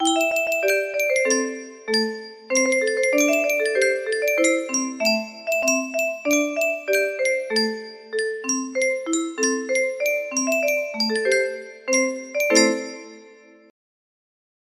Yunsheng Music Box - Unknown Tune Y523 music box melody
Full range 60